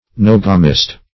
Search Result for " neogamist" : The Collaborative International Dictionary of English v.0.48: Neogamist \Ne*og"a*mist\ (n[-e]*[o^]g"[.a]*m[i^]st), n. [Gr. neo`gamos newly married.] A person recently married.